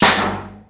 wrench_hit_metal1.wav